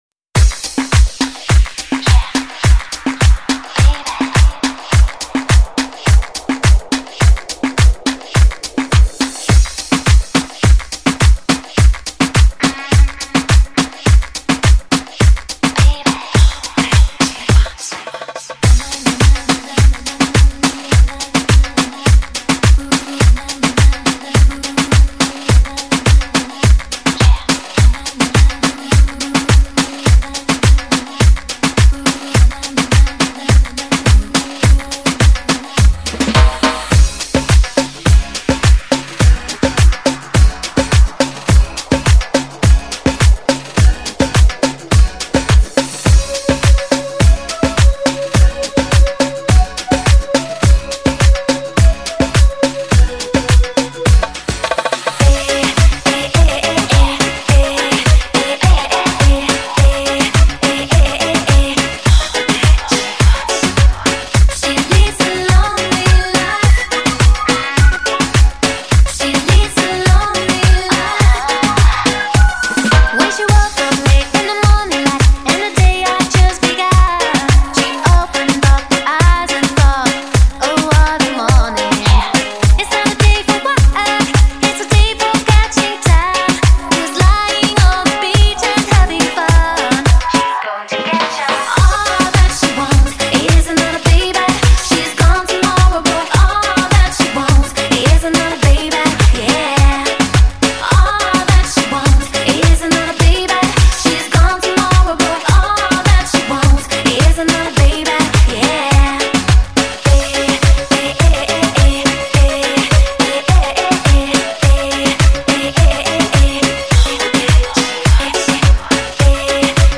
唱片类型：电音炫音
撩拨惹火的恣意节拍诱人陷溺的激情摇摆。